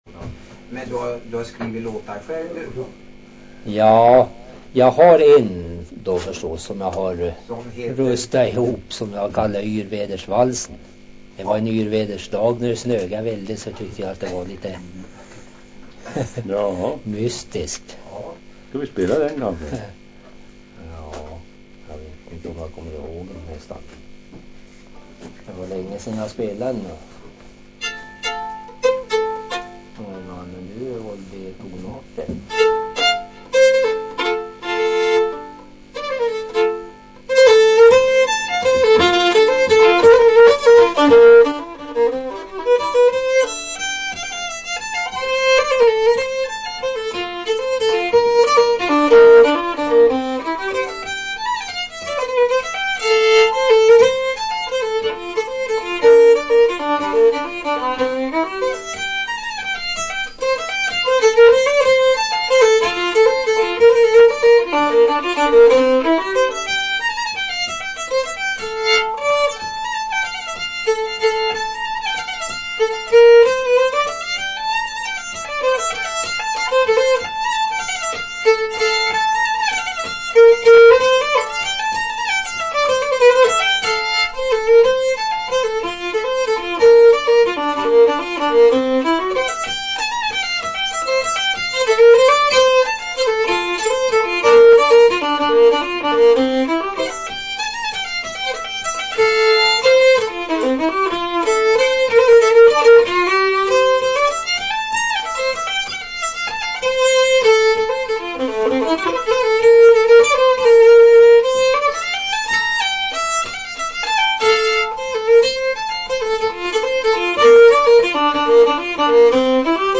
spelmannen